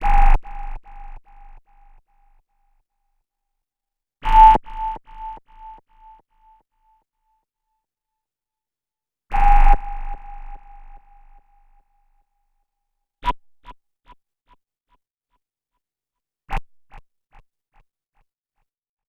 59-SONAR  -L.wav